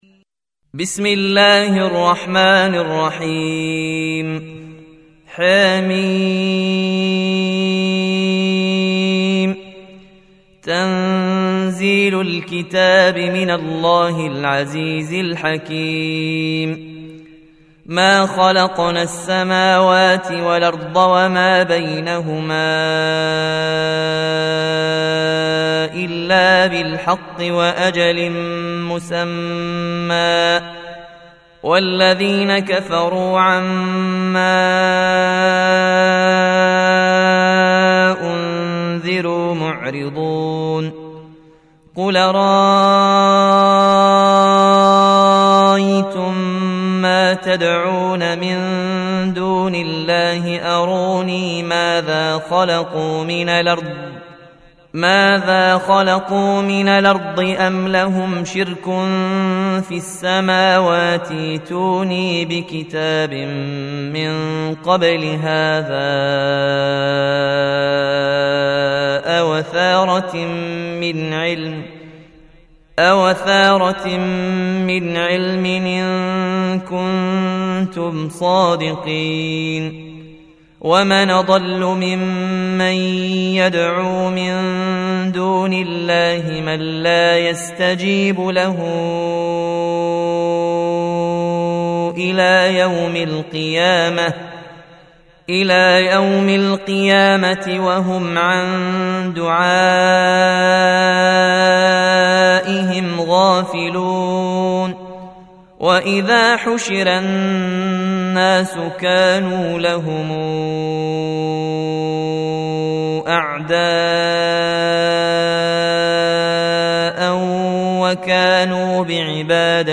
Surah Sequence تتابع السورة Download Surah حمّل السورة Reciting Murattalah Audio for 46. Surah Al-Ahq�f سورة الأحقاف N.B *Surah Includes Al-Basmalah Reciters Sequents تتابع التلاوات Reciters Repeats تكرار التلاوات